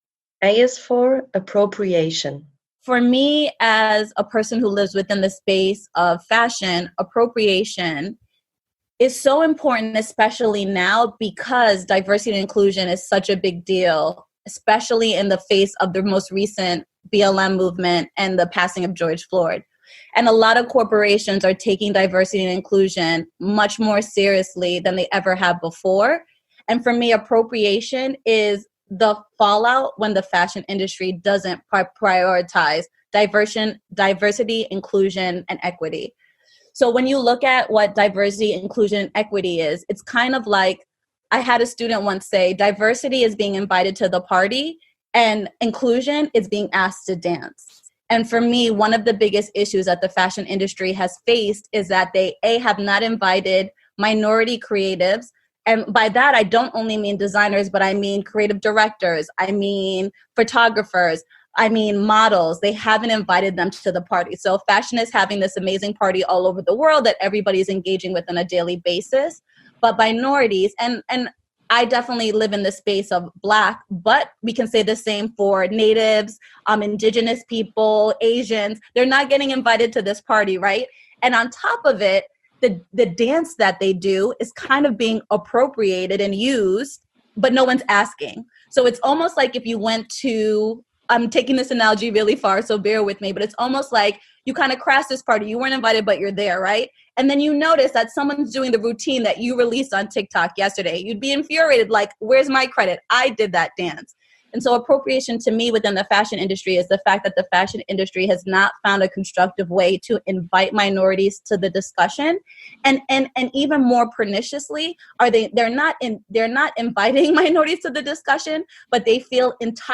Listen to two highly creative, profund and critical-engaged fashion minds.